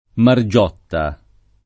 Margiotta [ mar J0 tta ]